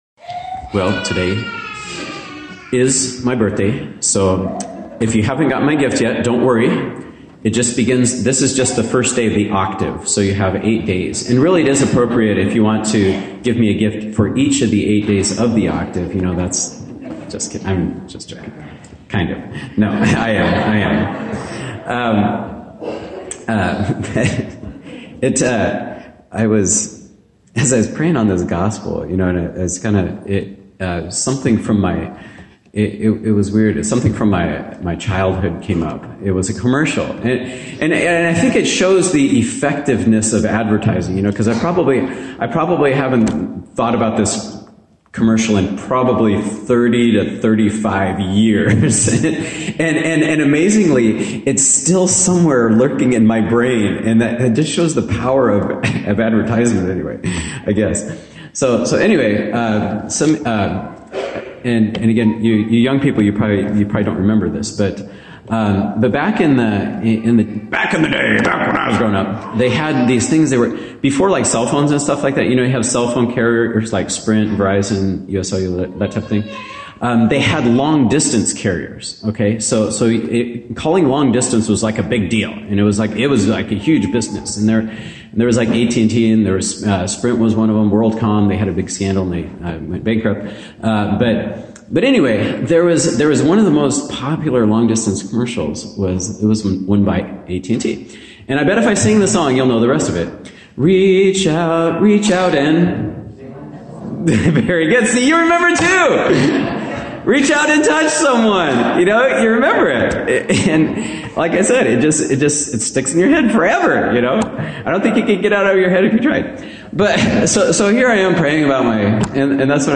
2018 Homilies
homilyvib18.mp3